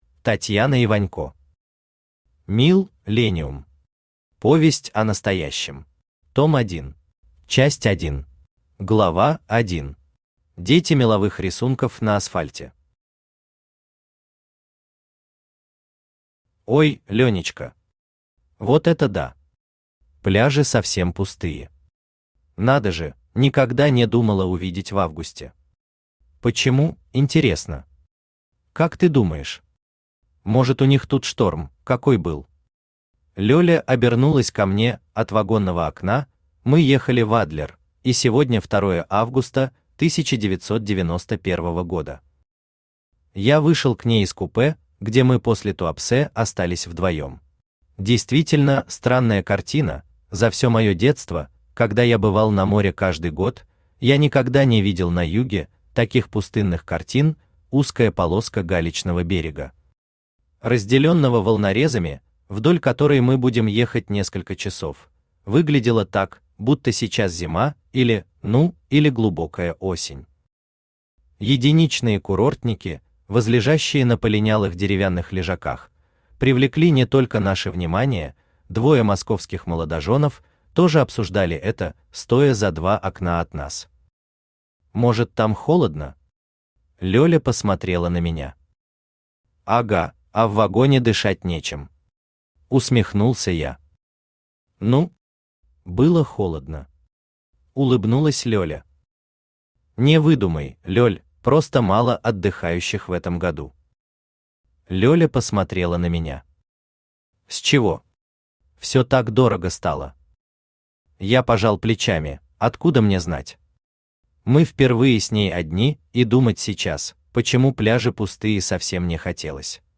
Аудиокнига МилЛЕниум. Повесть о настоящем. Том 1 | Библиотека аудиокниг
Том 1 Автор Татьяна Вячеславовна Иванько Читает аудиокнигу Авточтец ЛитРес.